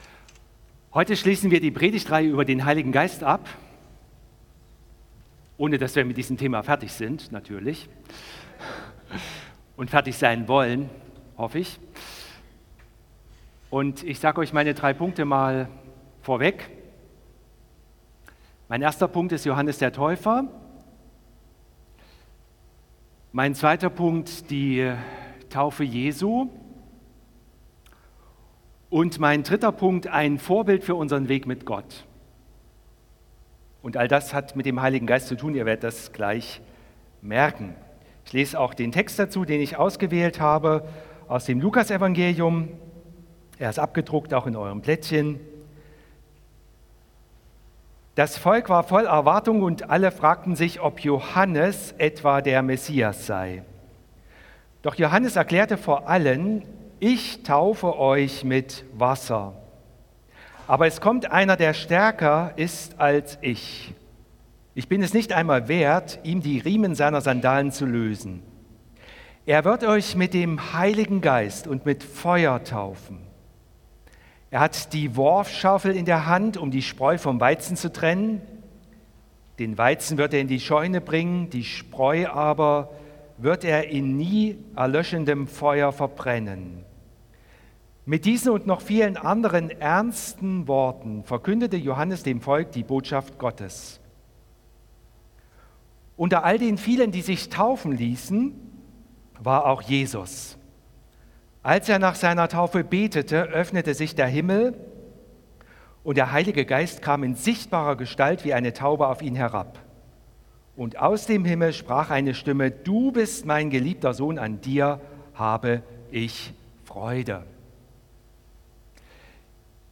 Media / Predigten - Andreasgemeinde Leipzig